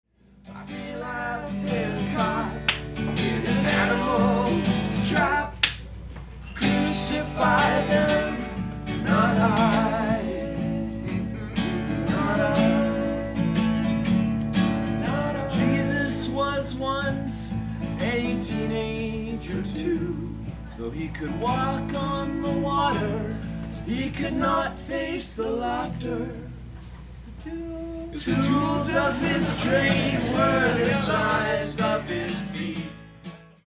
at Ultrasound Showbar in Toronto, Canada